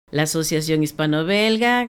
hispano_belga_prononciation.mp3